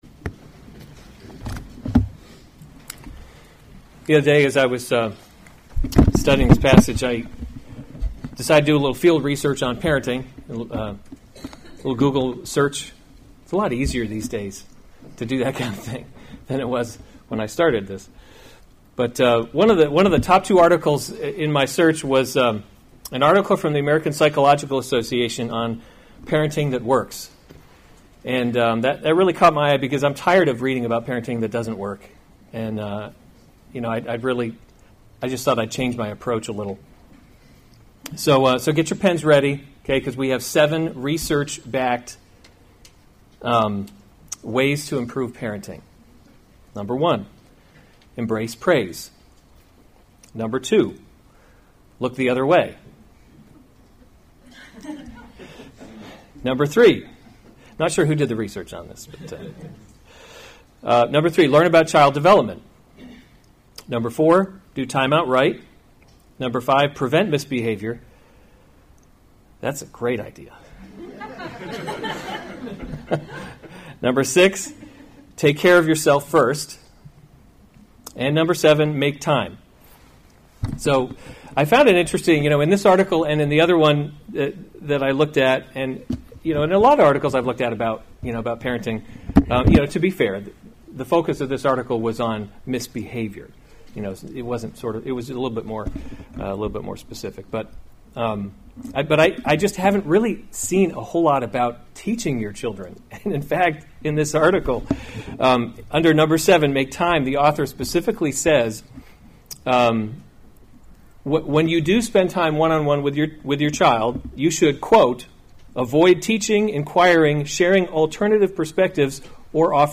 July 21, 2018 Psalms – Summer Series series Weekly Sunday Service Save/Download this sermon Psalm 78:1-8 Other sermons from Psalm Tell the Coming Generation A Maskil [1] of Asaph. 78:1 Give ear, […]